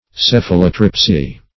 Search Result for " cephalotripsy" : The Collaborative International Dictionary of English v.0.48: Cephalotripsy \Ceph"a*lo*trip`sy\, n. [See Cephalotribe .]
cephalotripsy.mp3